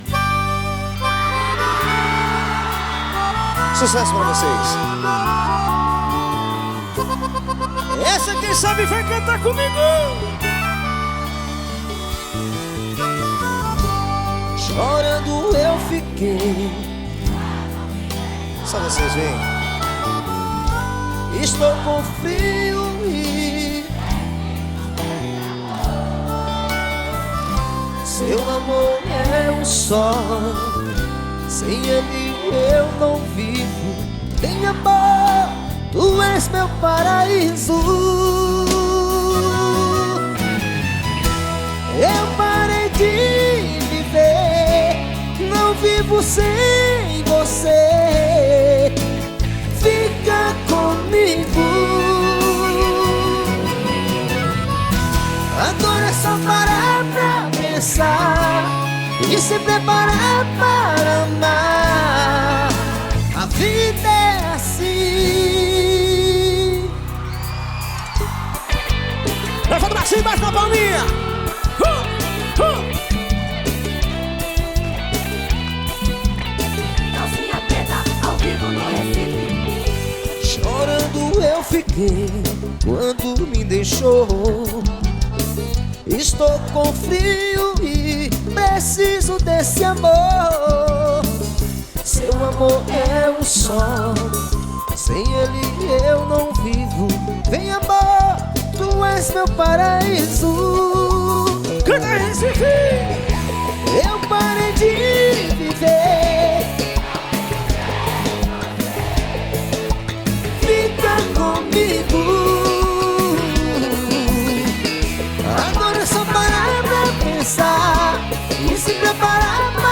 2024-12-30 11:02:29 Gênero: Forró Views